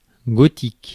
Ääntäminen
Synonyymit opus francigenum Ääntäminen France: IPA: [ɡɔ.tik] Haettu sana löytyi näillä lähdekielillä: ranska Käännös Ääninäyte Adjektiivit 1.